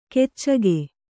♪ keccage